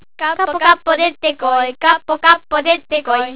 ためになる広島の方言辞典 か．